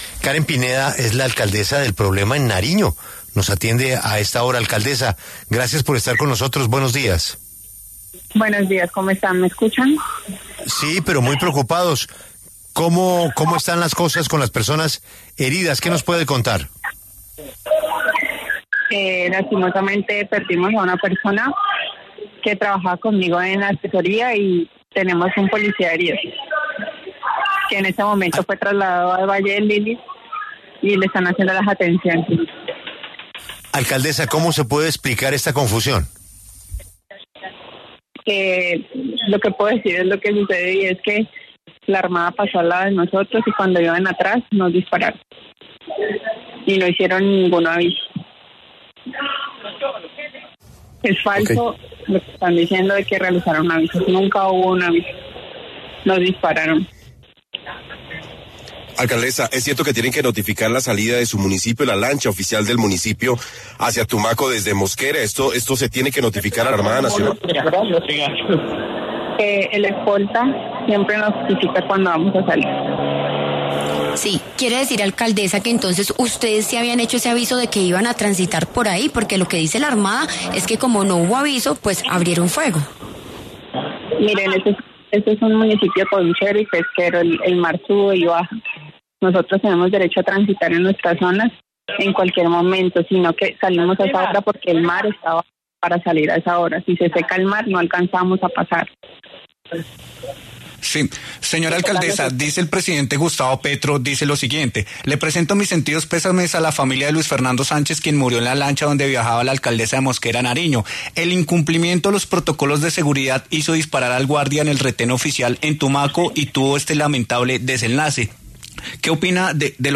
La alcaldesa de Mosquera (Nariño), Karen Pineda, habló en La W sobre la delicada situación de seguridad ocurrida en la madrugada de este 8 de septiembre cuando se transportaba hasta el distrito de Tumaco.